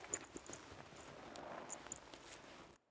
shared_stunned.wav